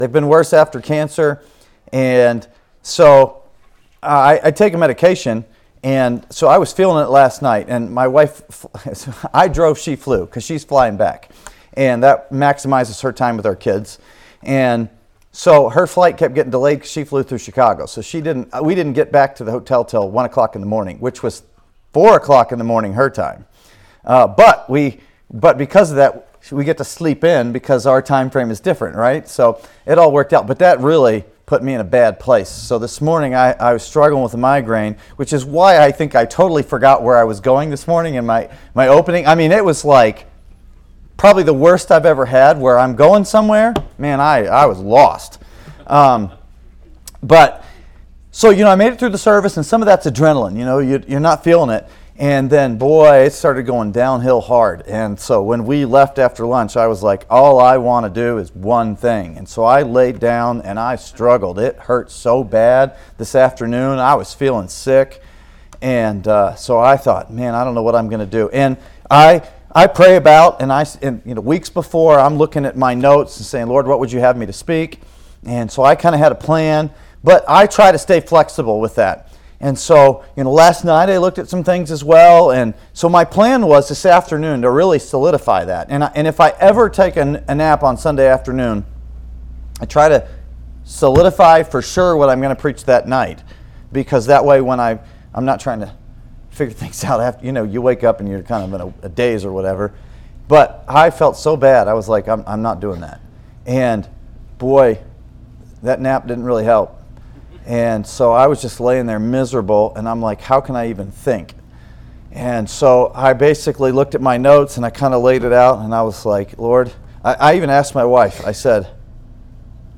Sermons | Grace Baptist Church
Revival-Service-2.mp3